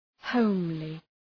{‘həʋmli:}